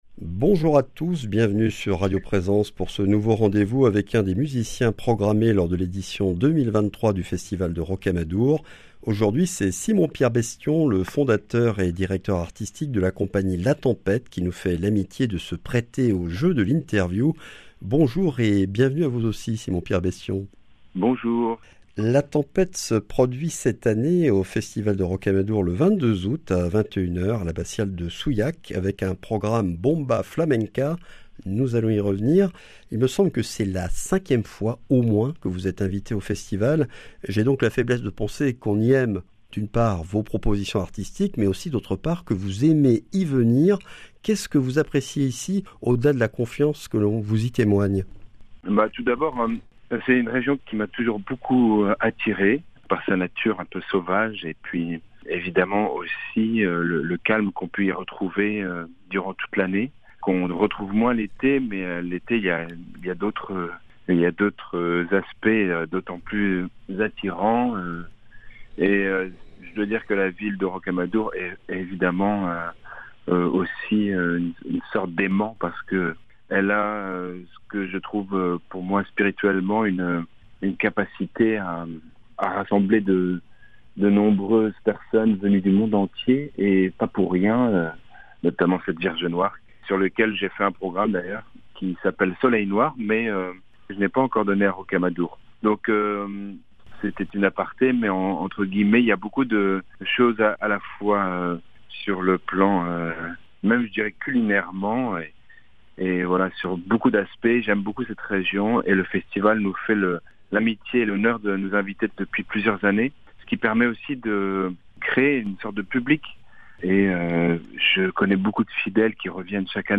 Festival de Rocamadour 2023, ITW du 22 août